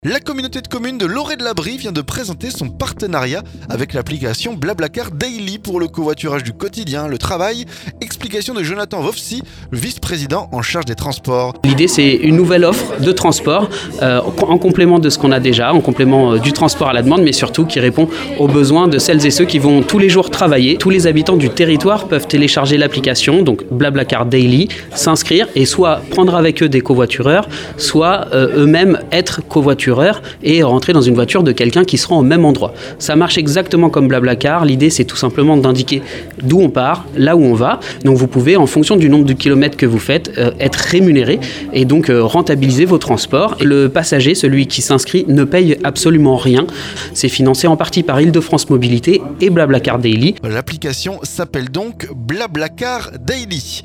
La communauté de communes de l'Orée de la Brie vient de présenter son partenariat avec l'application Bla Bla Car Daily. Pour le covoiturage du quotidien, pour le travail. Explications de Jonathan Wofsy, Vice-président en charge des transports.